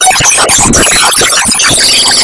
какаято девка чето поет на испанском или мексиканском )))